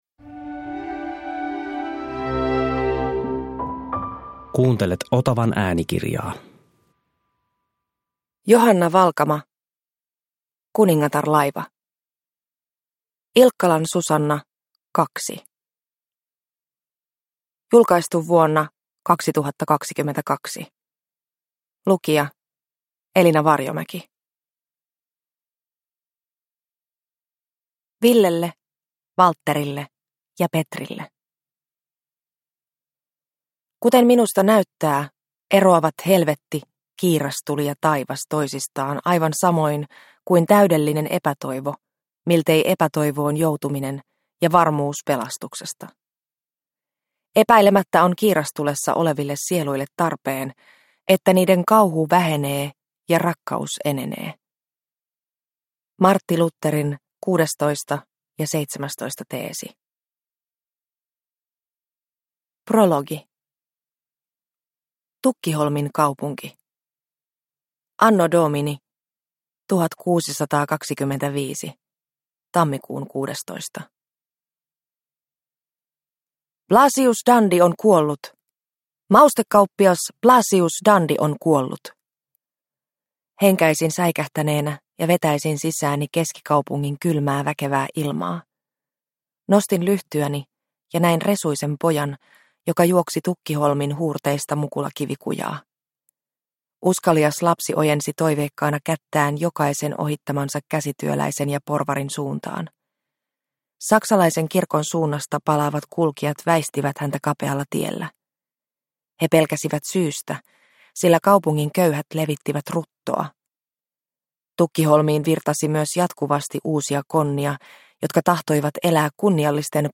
Kuningatarlaiva – Ljudbok – Laddas ner